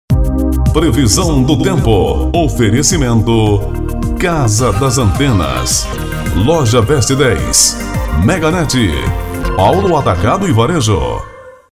Previsão do Tempo: